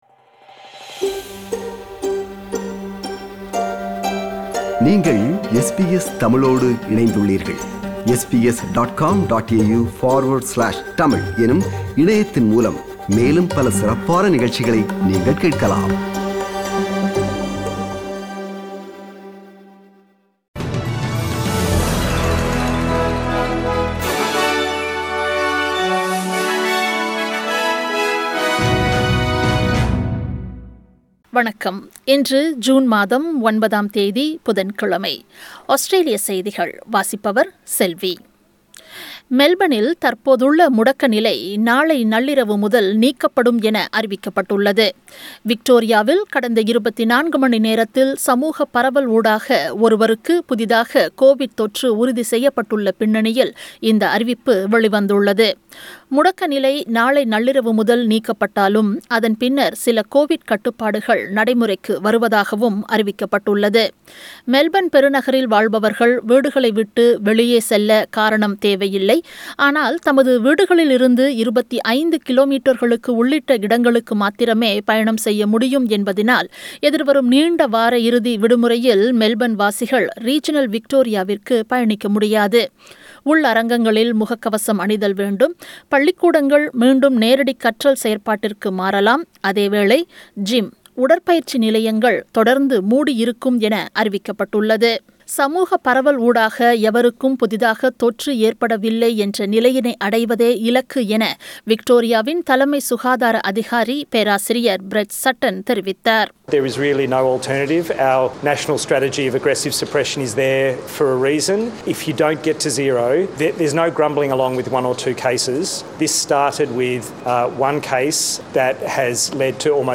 Australian News